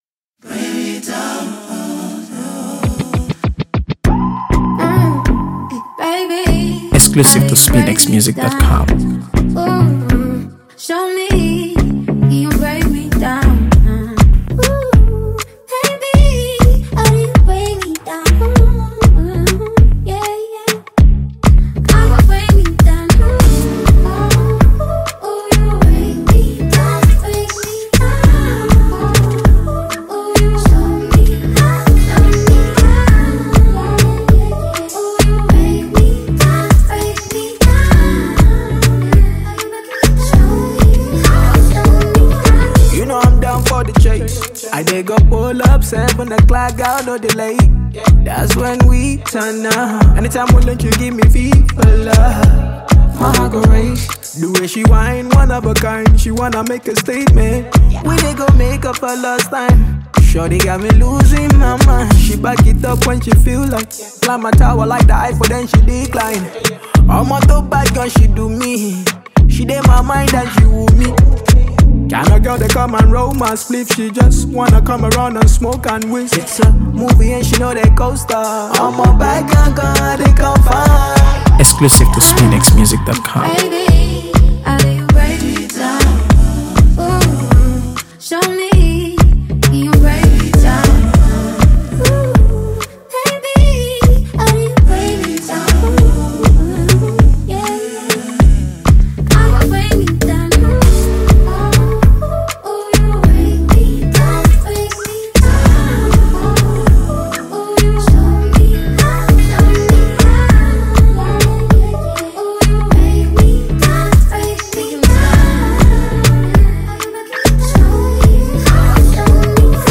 AfroBeats | AfroBeats songs
Emotional Ballad
Grammy-winning Nigerian singer-songwriter
heartfelt ballad
With its powerful lyrics and soulful delivery